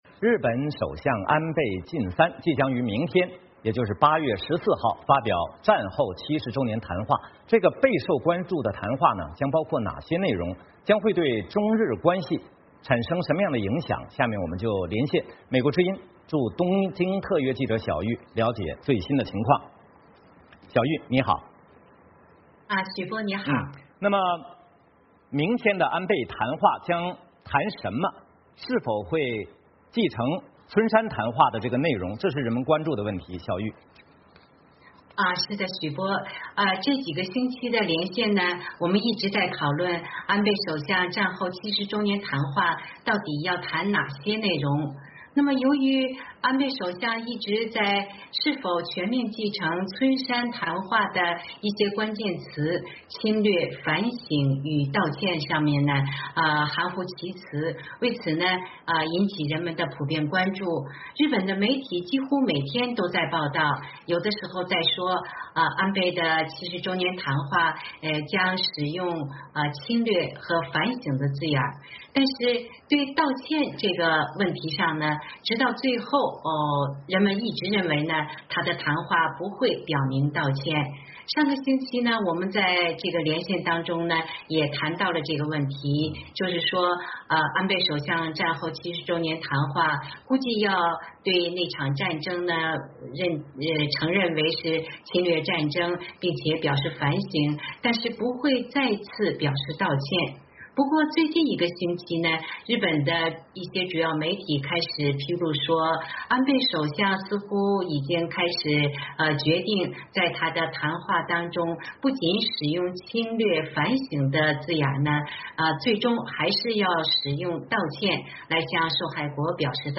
VOA连线：安倍战后70年谈话将包含道歉的关键词